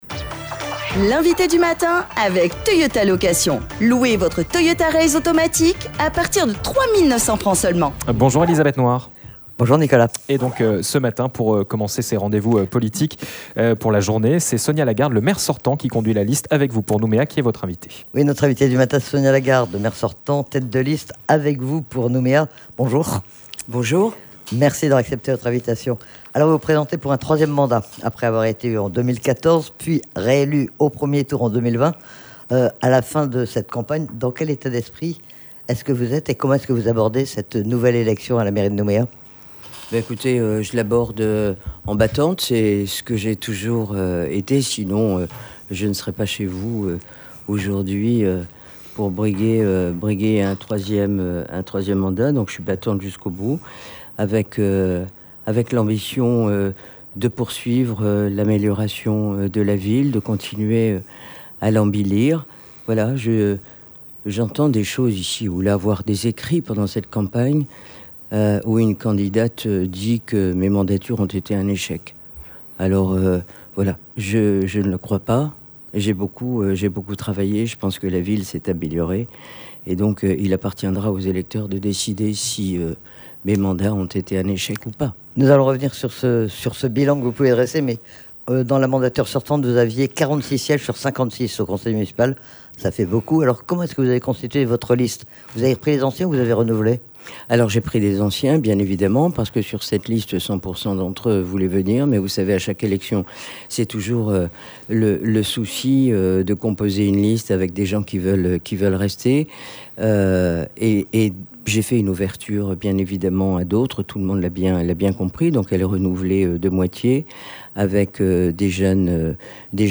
Ce matin, c'est Sonia Lagarde, le maire sortant, qui conduit la liste "Avec vous pour Nouméa", qui était notre invitée à 7h30. Elle était interrogée sur son bilan et sur son programme.